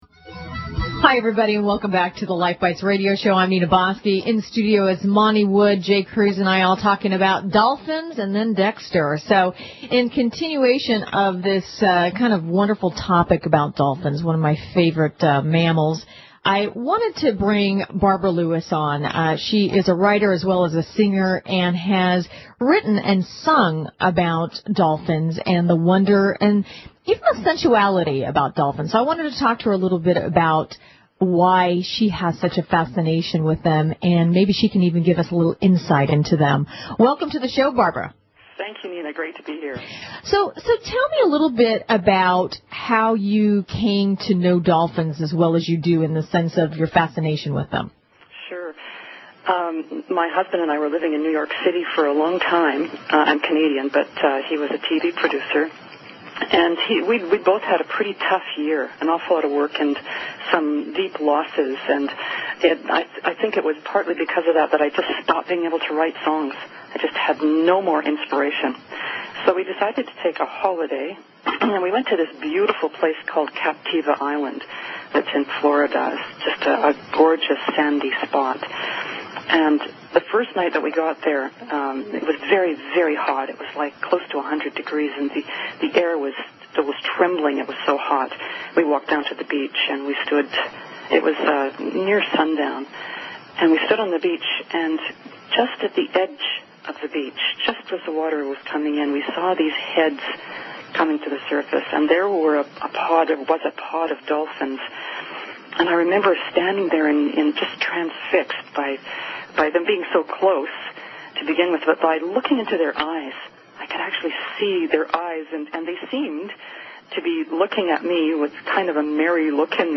• Interview